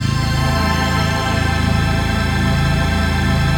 DM PAD2-06.wav